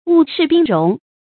霧釋冰融 注音： ㄨˋ ㄕㄧˋ ㄅㄧㄥ ㄖㄨㄙˊ 讀音讀法： 意思解釋： 霧氣消散冰塊融化。比喻疑難消除盡凈。